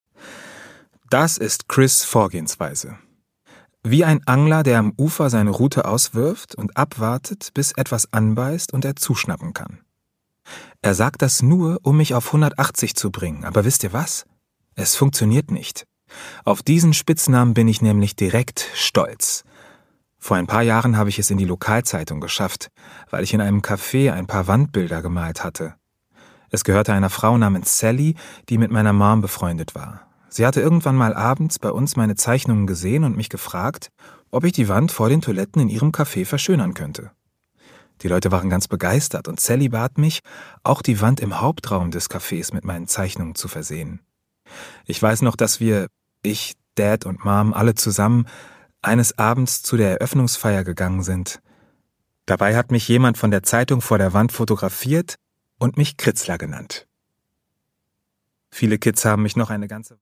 Produkttyp: Hörbuch-Download
Gelesen von: Benito Bause
Benito Bause, u. a. bekannt durch den erfolgreiche TV-Serie Doppelhaushälfte, erzählt warm und authentisch.